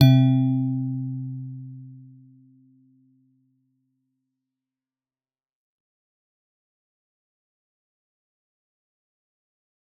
G_Musicbox-C3-f.wav